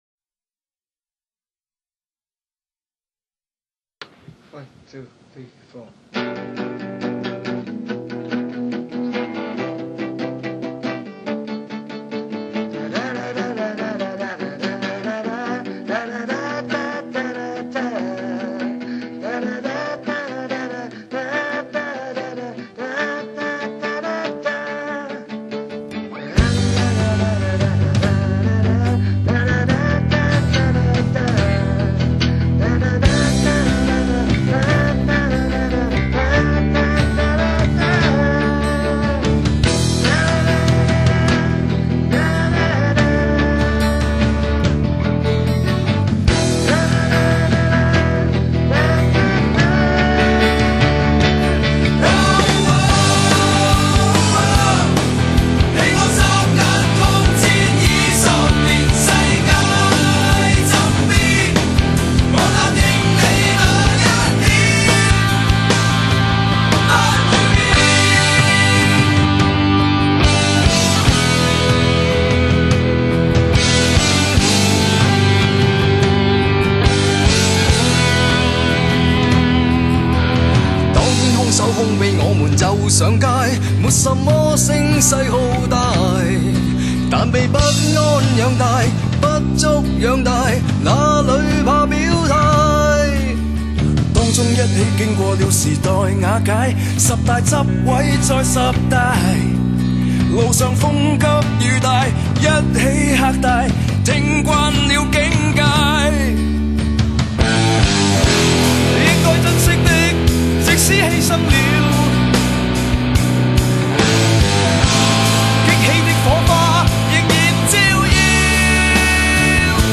音乐类型：流行